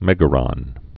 (mĕgə-rŏn)